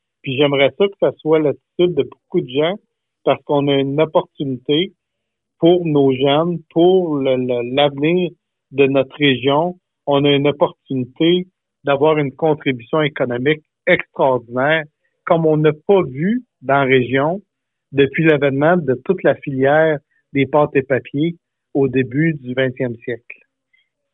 Sur les ondes de VIA 90.5 FM, le député de Bécancour-Nicolet a mentionné qu’il souhaiterait que la population soit plus optimiste à l’égard de ce projet.